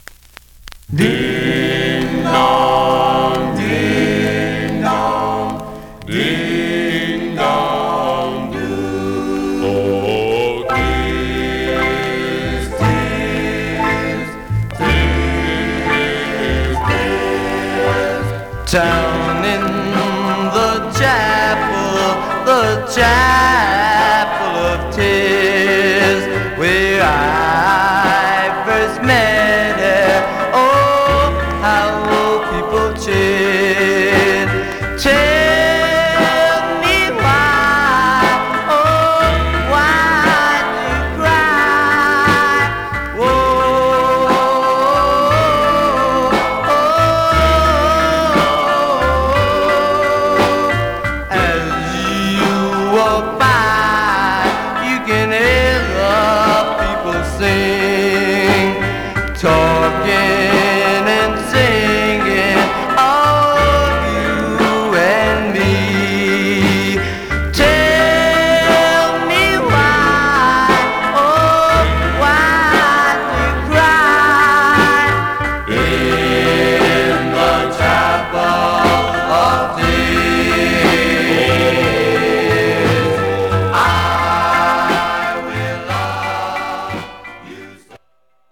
Condition Surface noise/wear Stereo/mono Mono